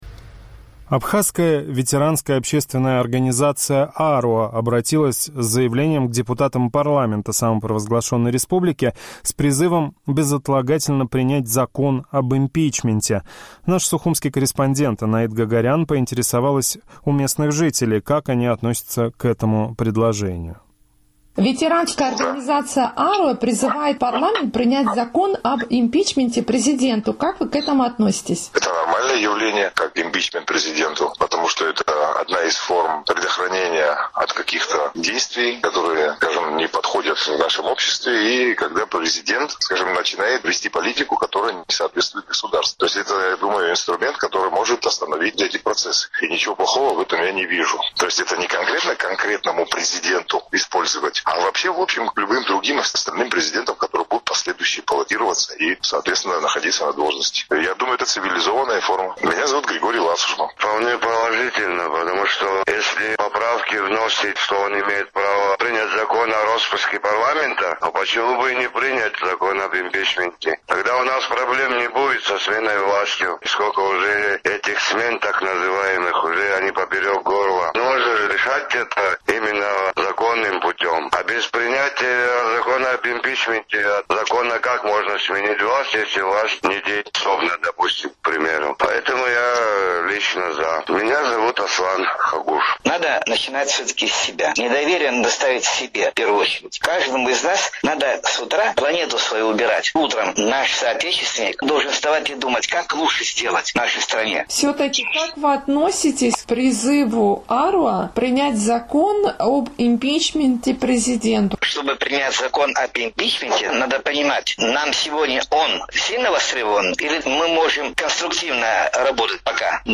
Сухумский опрос – о законе об импичменте
Абхазская ветеранская общественная организация «Аруаа» обратилась с заявлением к депутатам парламента с призывом безотлагательно принять закон «Об импичменте президента». «Эхо Кавказа» поинтересовалось у местных жителей, как они относятся к этому предложению.